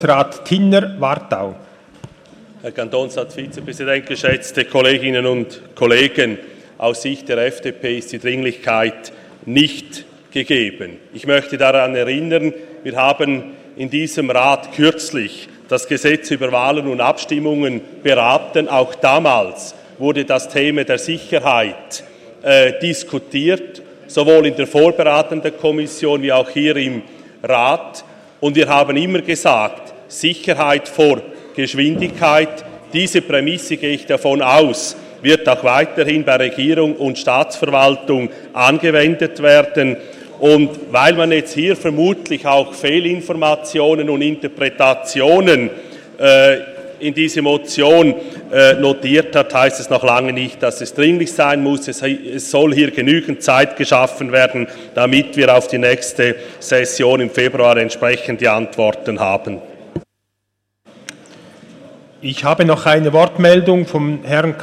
Session des Kantonsrates vom 26. bis 28. November 2018